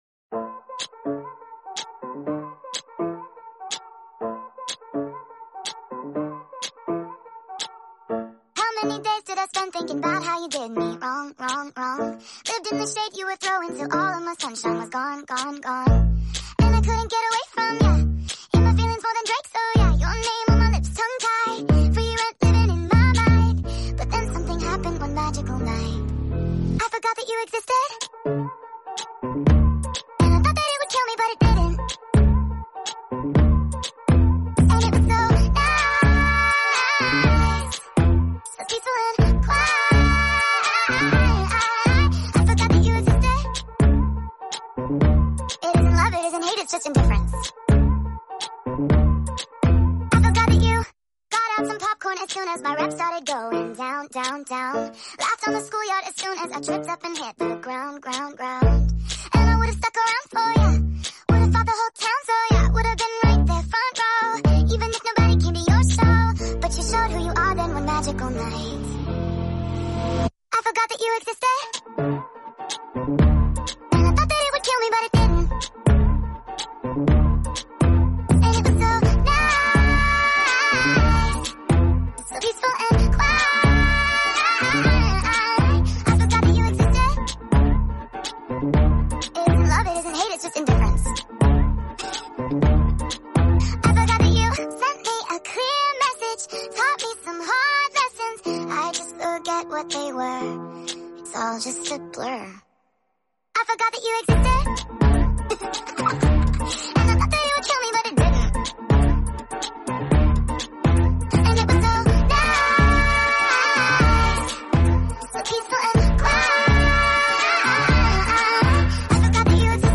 sped up full song